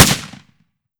7Mag Bolt Action Rifle - Gunshot B 005.wav